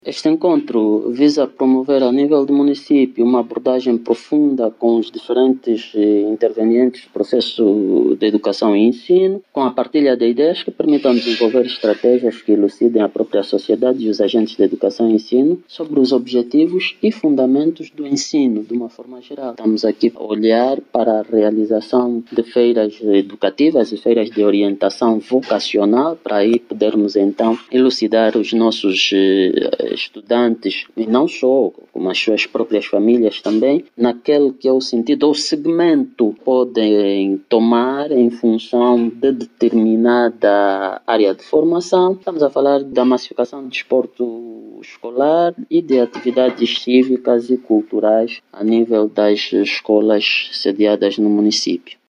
Durante dois dias, o evento, vai decorrer sob lema “Vencer os desafios e lançar perspetivas para um ensino de qualidade”, e vai olhar para as estratégias do fortalecimento da relação família-escola, a gestão da sala de aulas e os desafios da relação escola-empresa. Como assegura o Director Municipal da Educação do Kilamba, Kizunga Pedro Lundulo.
KIZUNGA-PEDRO.mp3